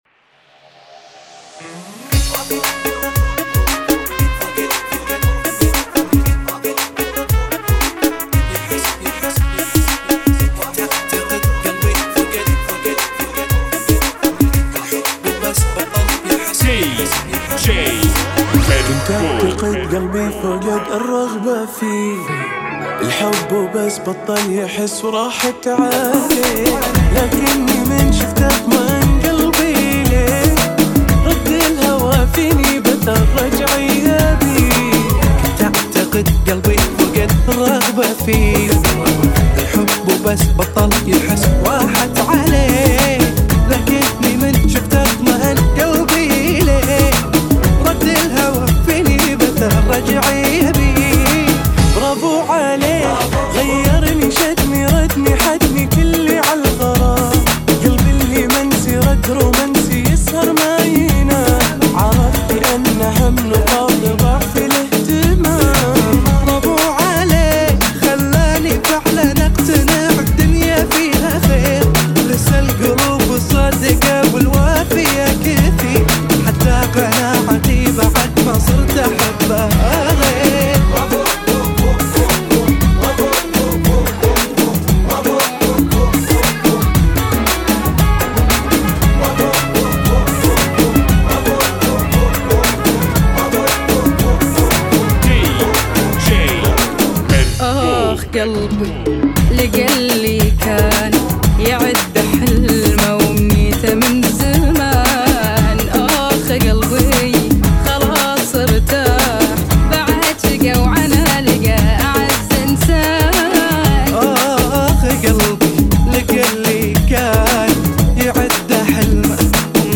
[ 116 bpm ]